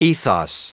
Prononciation du mot ethos en anglais (fichier audio)
Prononciation du mot : ethos